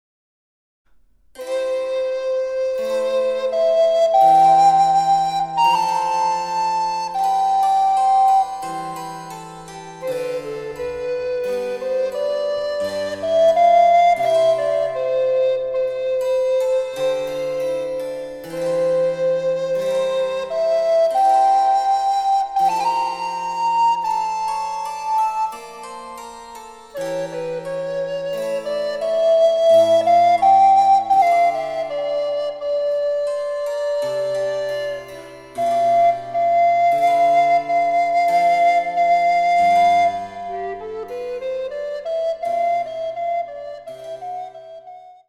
★アルトリコーダー用の名曲をチェンバロ伴奏つきで演奏できる、「チェンバロ伴奏ＣＤブック」です。
(3)各楽章につきリコーダーの演奏を合わせた演奏例